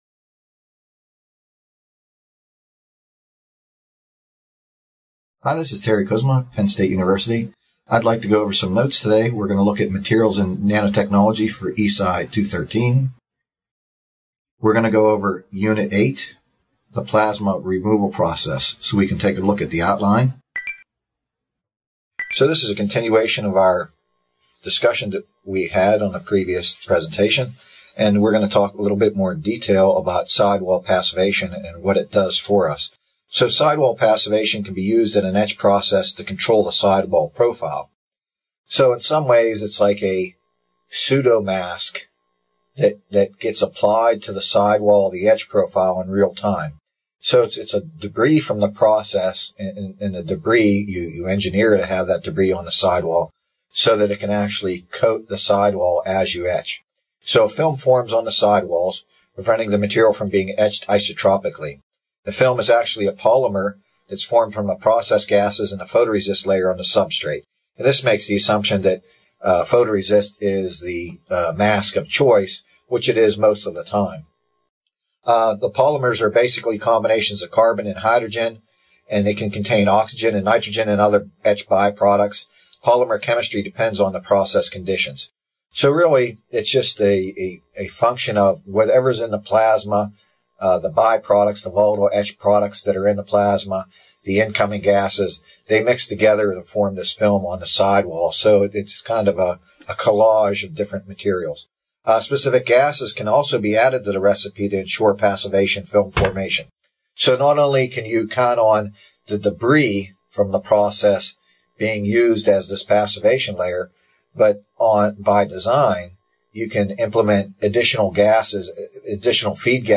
This video, provided by the Nanotechnology Applications and Career Knowledge Support (NACK) Center at Pennsylvania State University, is part two of a two-part lecture on plasma and nanotechnology materials.